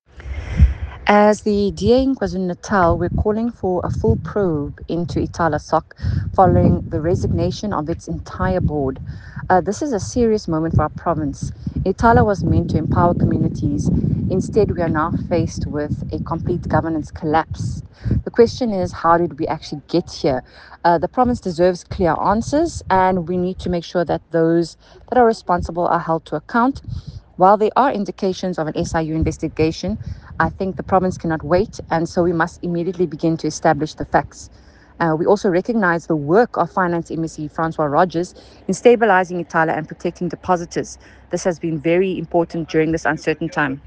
Note to Editors: Please note Hannah Lidgett, MPL sound bite in